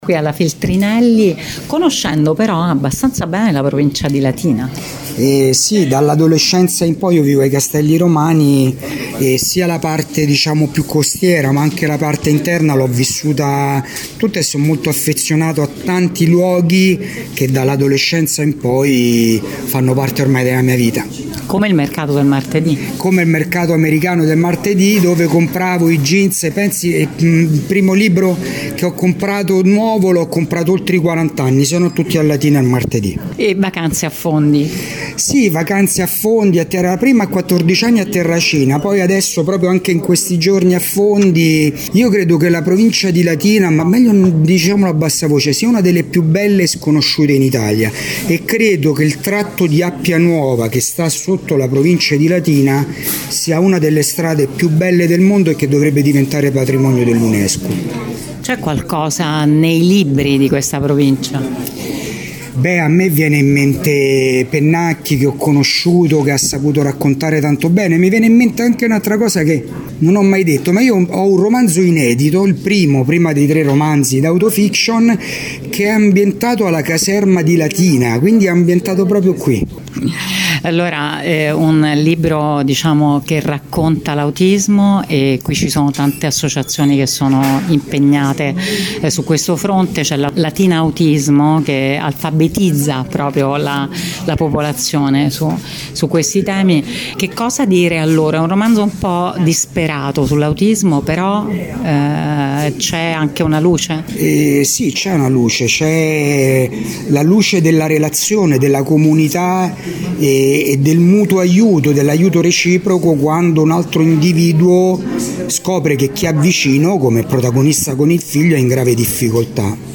LATINADaniele Mencarelli, lunedì pomeriggio accomodato in poltrona nella vetrina della Feltrinelli per presentare il suo ultimo romanzo Fame d’aria (Mondadori), confessa di avere un inedito nel cassetto, praticamente il primo dei suoi quattro romanzi, ambientato esattamente nella caserma di Latina.
Ma che sia anche capace di inchiodare persone di vario genere alla sedia mentre parla a braccio del suo romanzo sull’autismo, è una bella sorpresa.